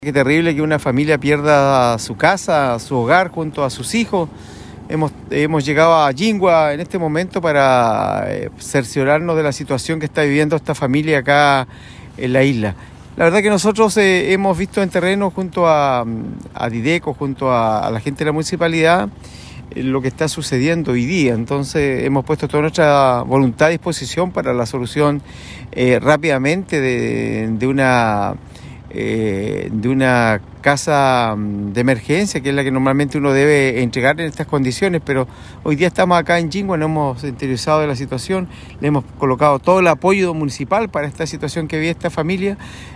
El edil René Garcés expresó que la situación de la familia es lamentable, sin embargo, el municipio se puso a disposición de las personas damnificadas y esperan comenzar a entregarles lo básico para su subsistencia durante esta semana.